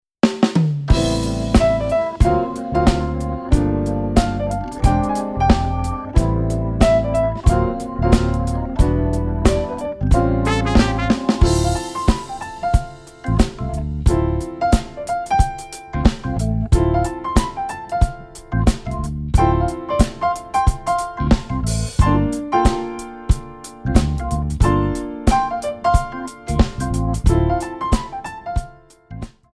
Hip-Hop / Lyric Jazz
8 bar intro
up-beat